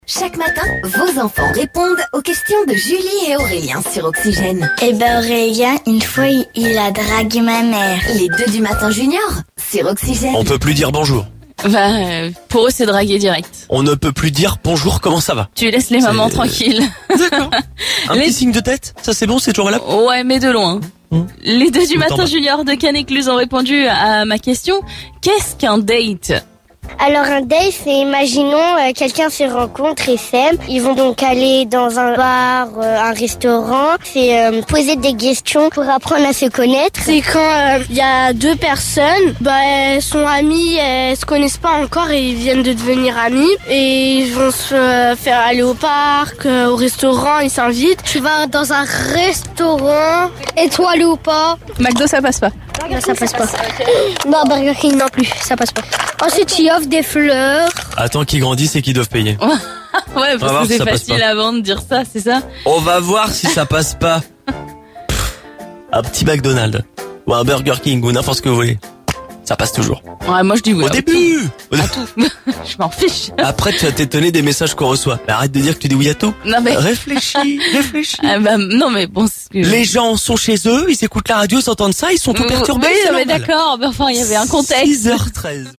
Écouter le podcast Télécharger le podcast Écoutons les enfants Seine-et-Marnais nous expliquer ce que ça veut dire "avoir un date" ?